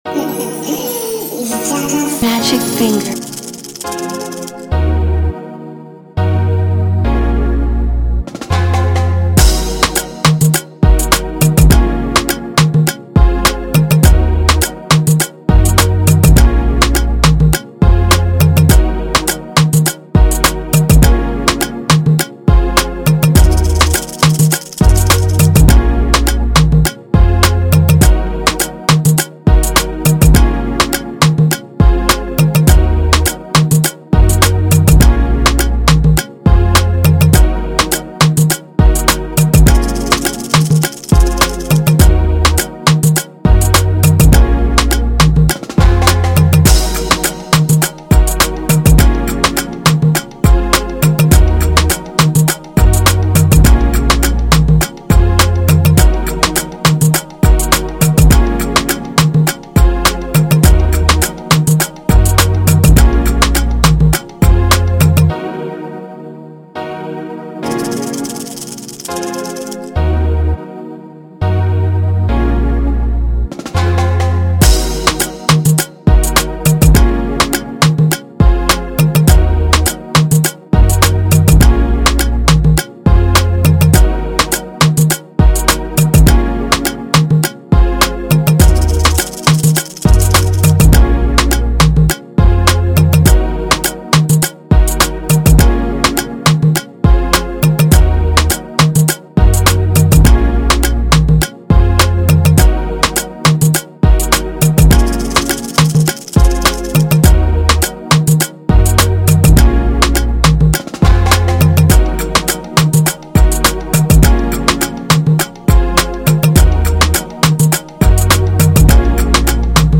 December 31, 2019 admin Instrumentals 0